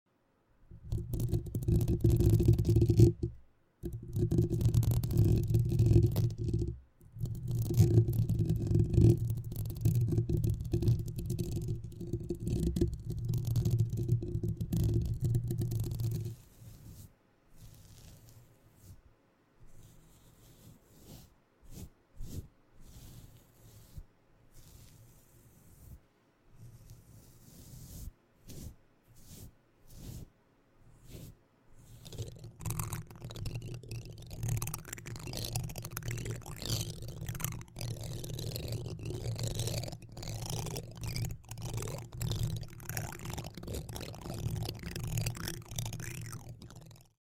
3 Bare mic triggers including sound effects free download
3 Bare mic triggers including mic scratching, mic brushing, and spoons on mic.